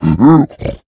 sounds / mob / piglin / idle1.ogg